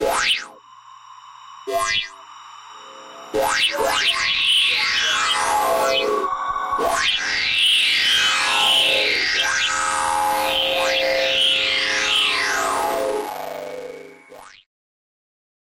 The sounds are mostly space-age, weird naughty noises, and buzzy things -- cutting edge for 1976.